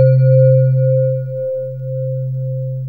VIBE RHODES.wav